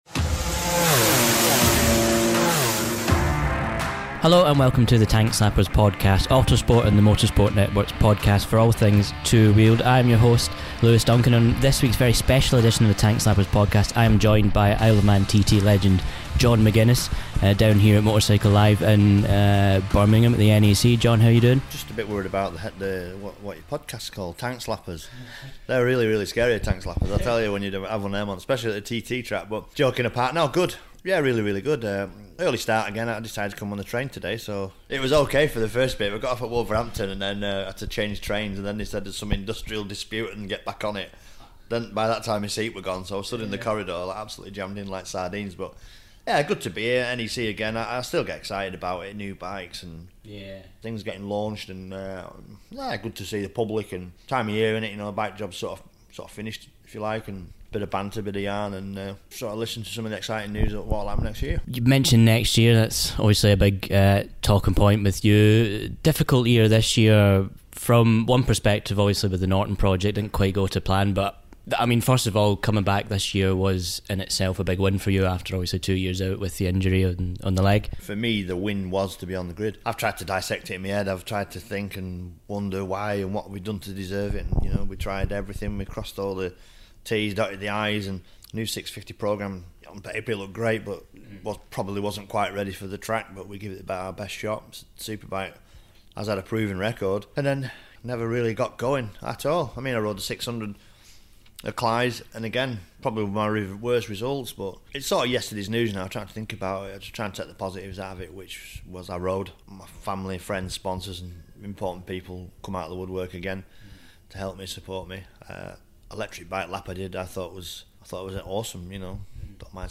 Interview: Isle of Man TT Legend John McGuinness - Episode #13
In a special edition of the Tank Slappers podcast, we sit down with Isle of Man TT legend John McGuinness.